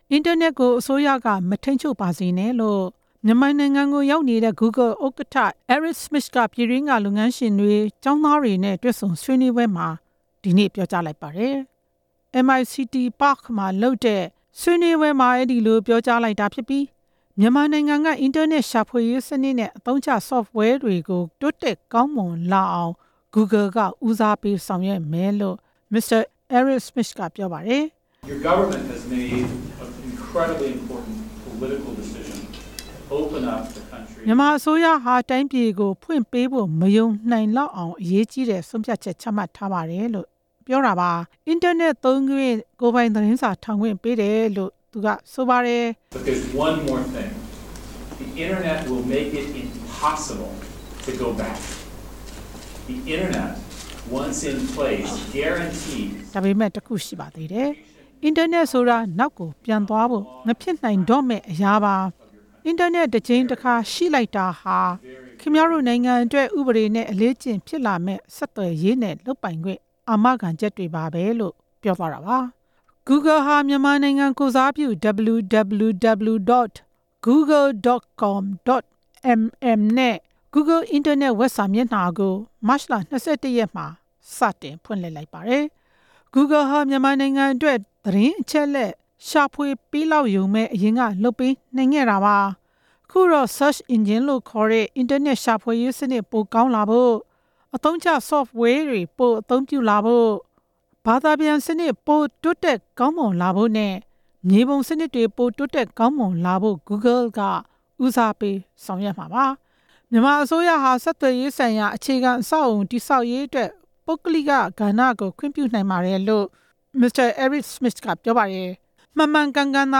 ဂူဂယ် ဥက္ကဌ Eric Schmidt ရဲ့ ပြောကြားချက်